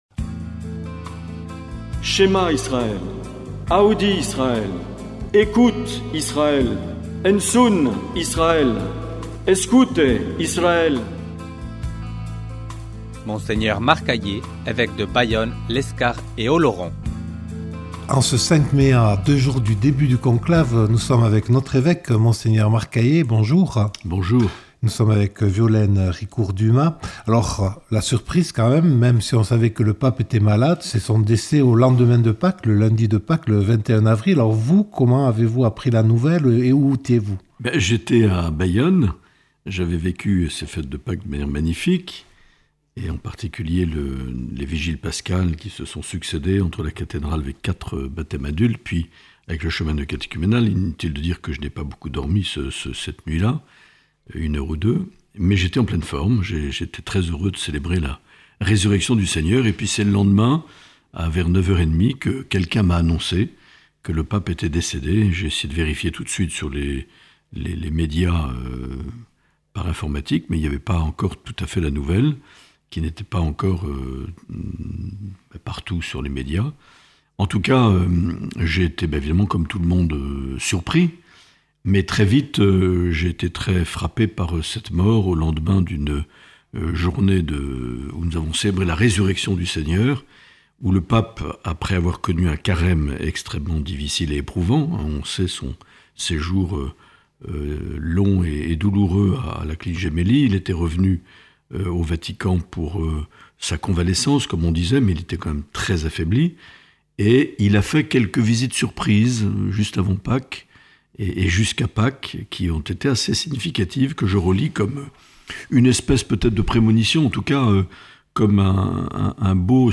Dans cet entretien enregistré le 4 mai 2025, Mgr Marc Aillet répond aux questions de Radio Lapurdi et de Radio Présence Lourdes Pyrénées.